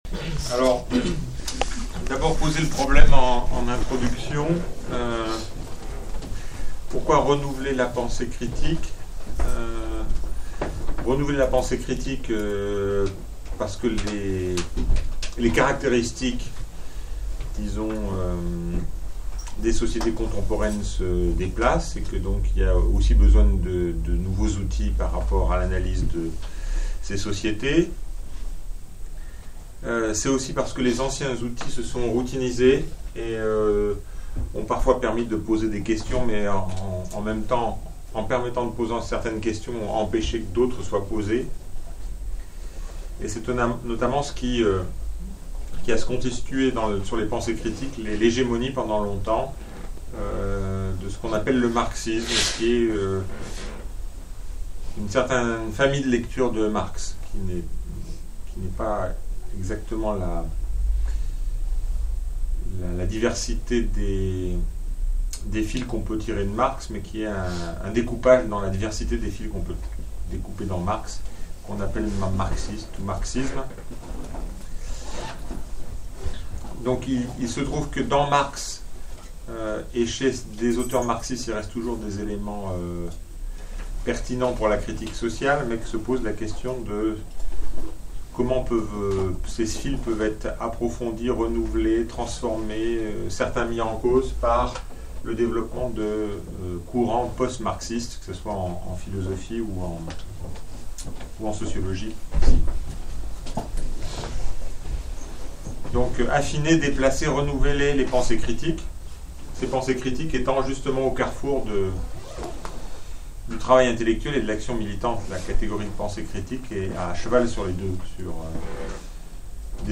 Conférence à la librairie lyonnaise « Terre des livres » - La Brèche numérique